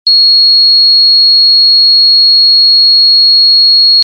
SIRENA 8 TONOS
Sirena con 8 tonos diferentes
Tono_1